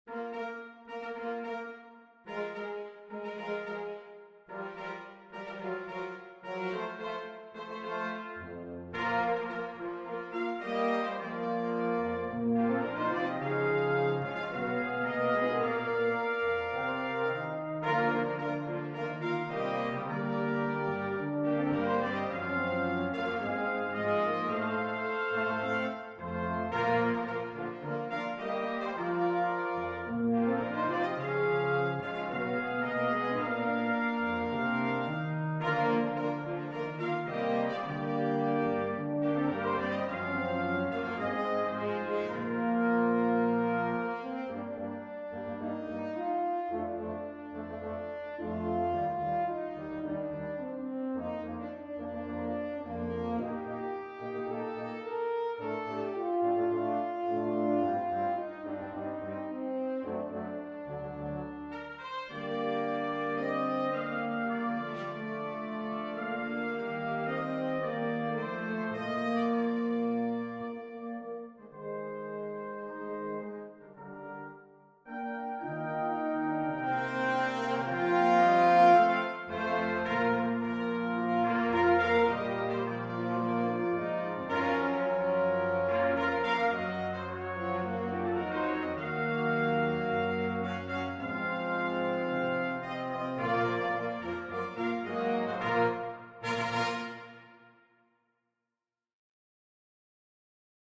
2 Trumpets,Horn,2 Trombones,Tuba